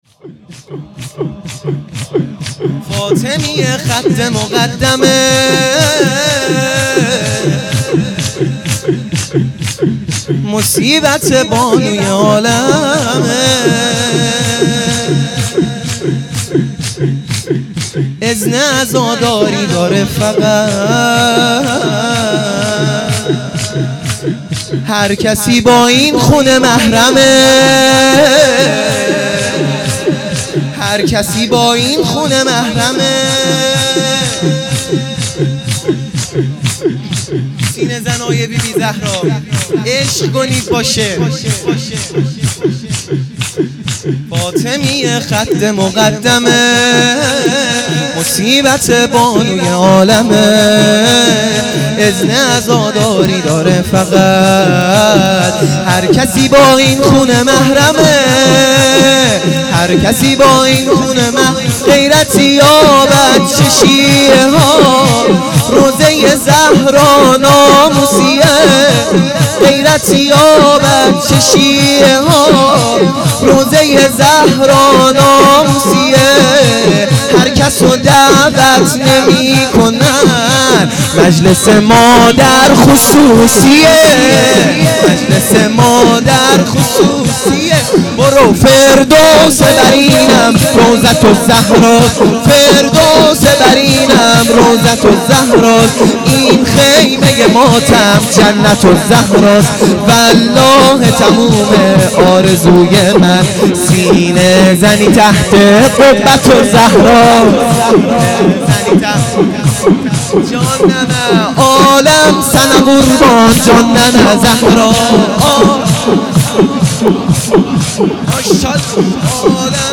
شور | فاطمیه خط مقدمه